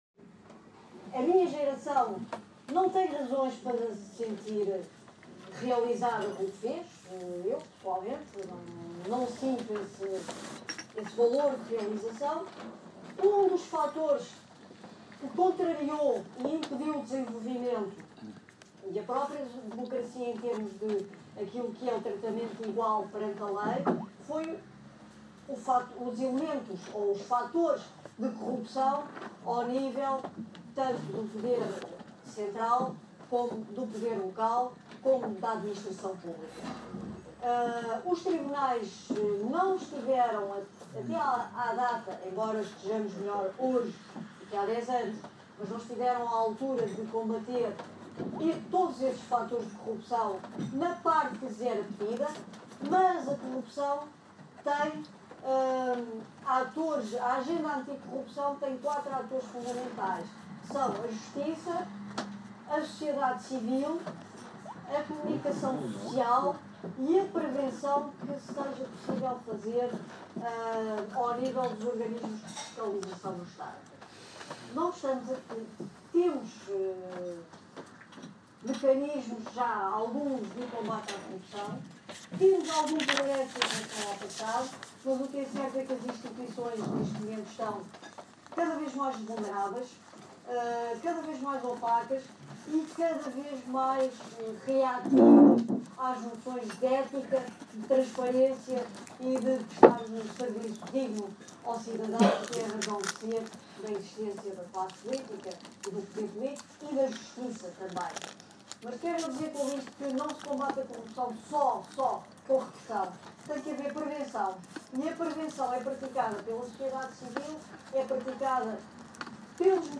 Maria José Morgado na Associação 25 de Abril, no dia 16 de Abril de 2014. Há 40 anos o Saldanha Sanches estava na prisão e não imaginava que daí a dias seria livre.